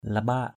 /la-ɓa:ʔ/ (cv.) limbak l{O%K 1.